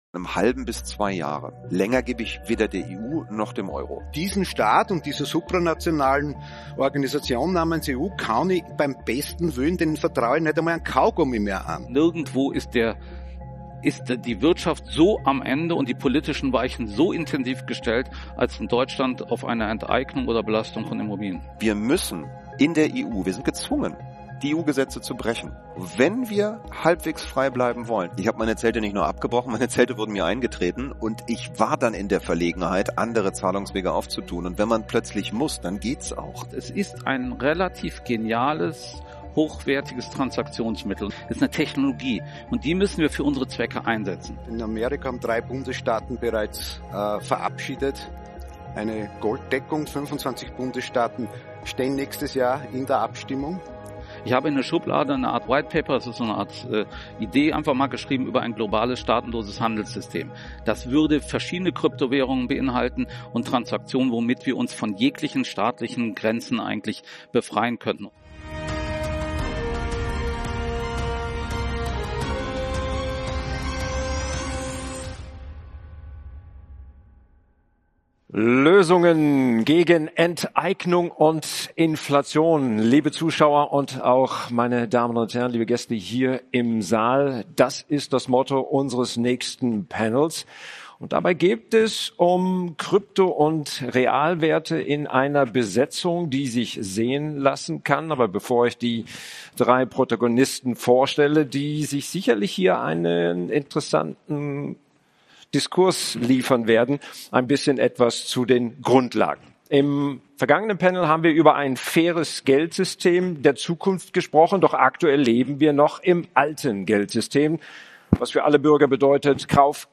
Ein Panel über finanzielle Alternativen, Grenzen – und die Suche nach Handlungsfähigkeit.